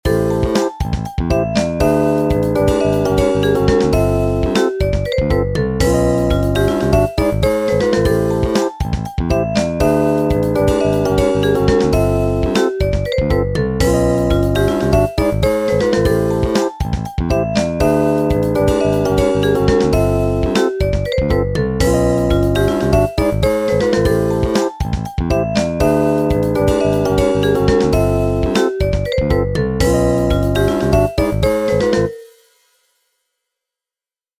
Short 120bpm loop in 20edo
20edo_demo.mp3